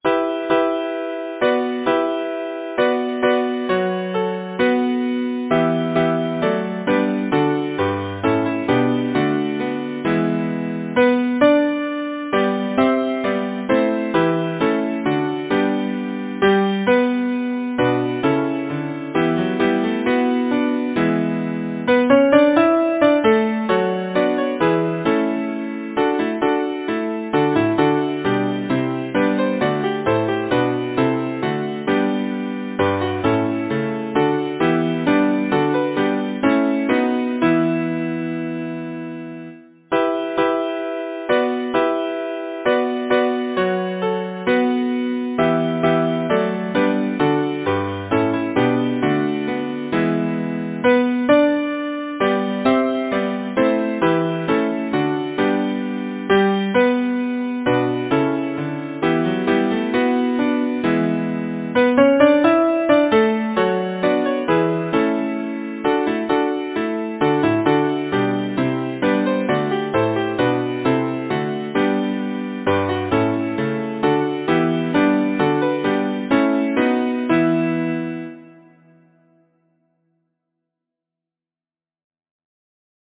Title: The Agincourt Song Composer: Geoffrey Shaw Lyricist: Anonymous Number of voices: 4vv Voicing: SATB Genre: Secular, Partsong, Folksong
Language: English Instruments: A cappella